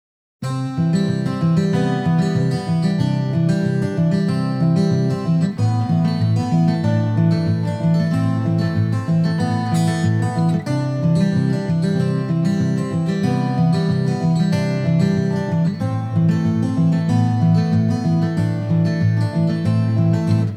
• Качество: 320, Stereo
гитара
спокойные
без слов
инструментальные